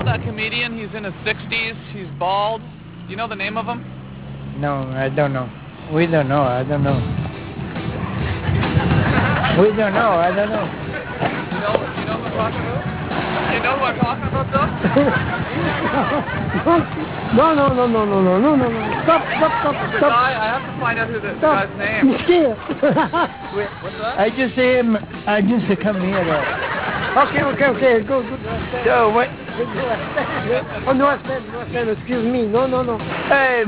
giggling men
gigglingmen.wav